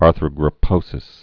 (ärthrə-grə-pōsĭs)